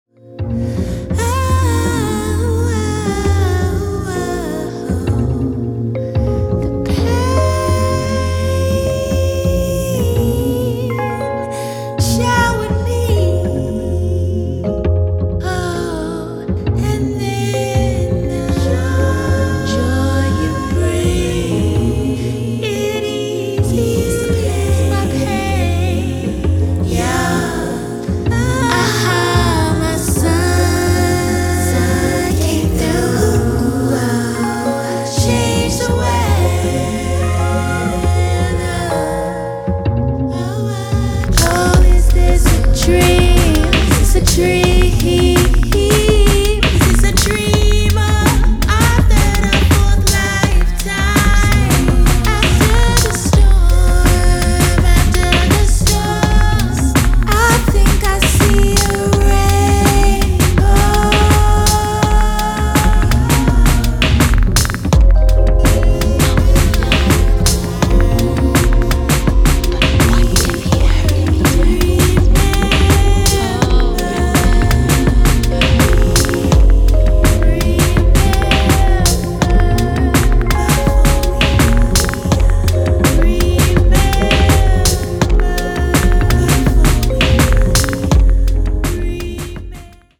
where post-modern soul meets dub lullabies and much more.